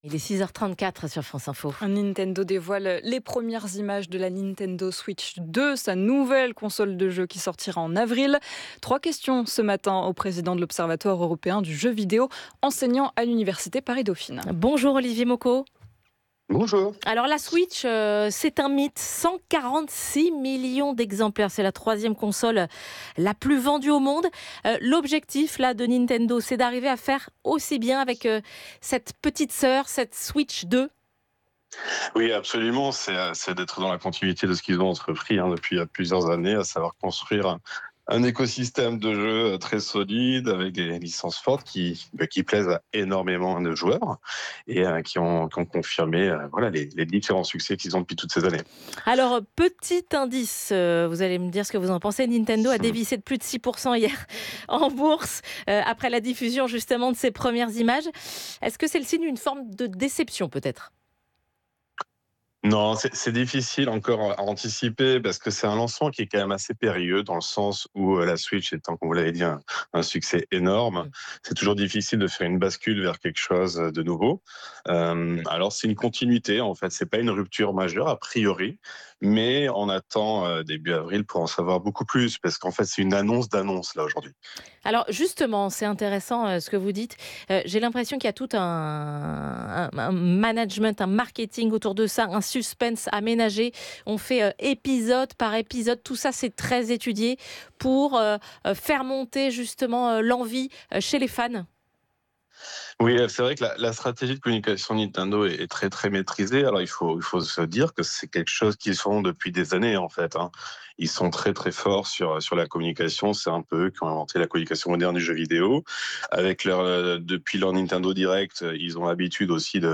itwfranceinfo.wav